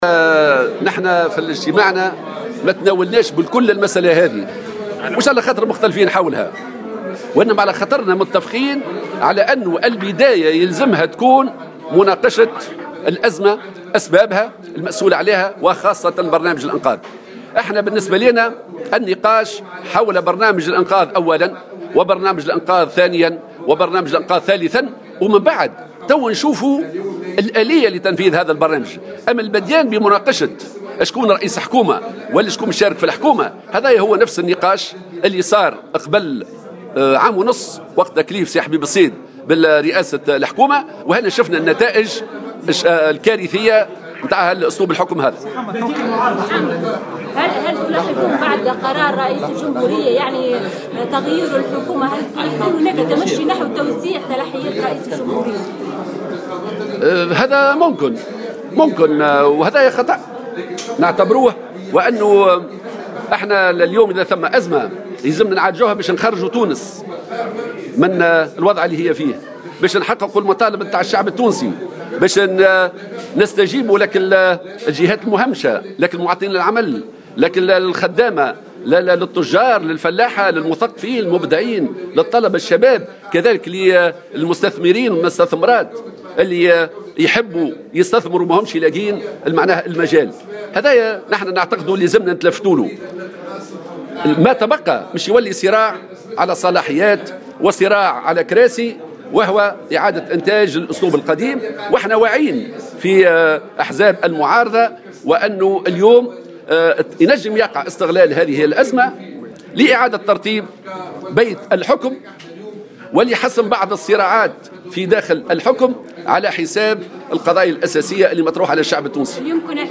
وقال في تصريحات صحفية على اثر اجتماع موسع لقوى المعارضة اليوم الأربعاء إن الجبهة الشعبية كانت تقدمت بمبادرة انقاذ تونس من الأزمة وهي المبادرة التي بصدد دراستها المعارضة.